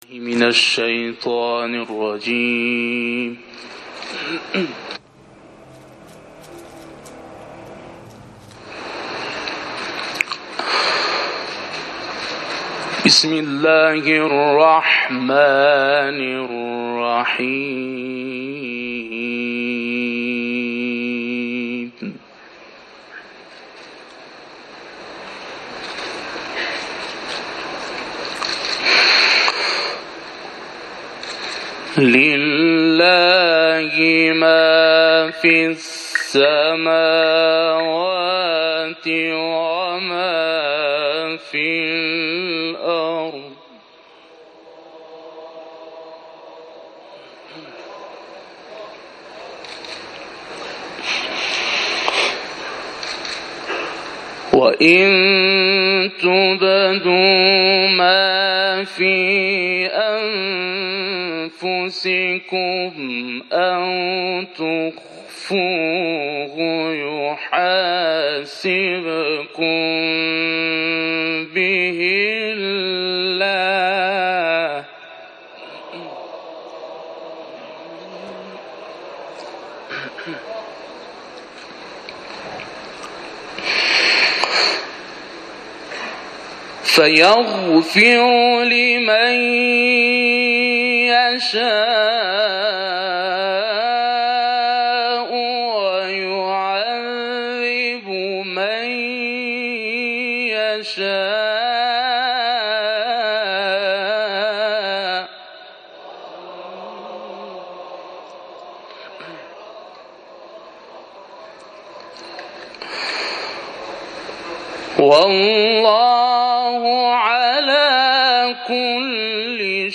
تلاوت
به گزارش خبرنگار فرهنگی باشگاه خبرنگاران پویا، جامعه قرآنی کشور طبق روال هر ساله، در نخستین روز ماه مبارک رمضان (شنبه 6 خردادماه) به دیدار رهبر انقلاب رفتند.